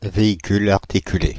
Ääntäminen
Synonyymit semi-remorque dix-huit roues Ääntäminen France (Île-de-France): IPA: [ve.i.kul‿aʁ.ti.ky.le] Haettu sana löytyi näillä lähdekielillä: ranska Käännöksiä ei löytynyt valitulle kohdekielelle.